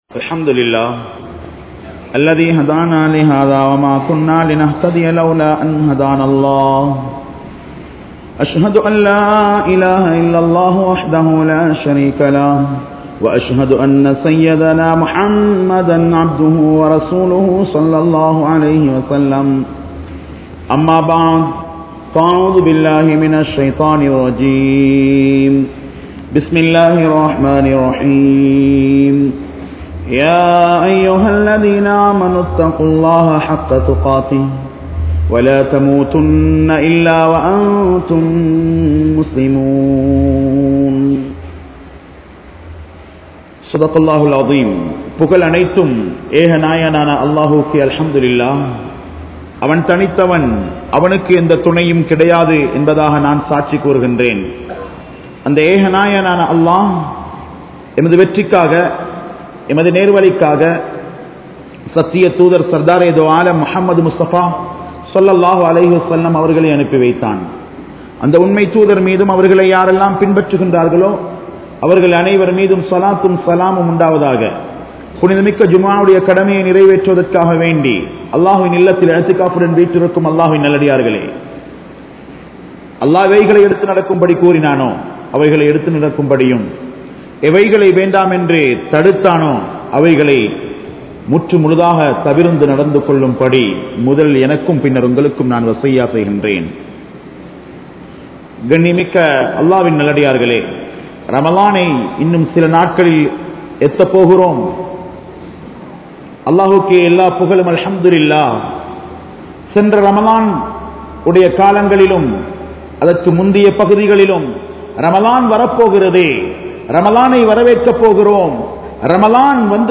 Ramalaanilum Paavama? (றமழானிலும் பாவமா?) | Audio Bayans | All Ceylon Muslim Youth Community | Addalaichenai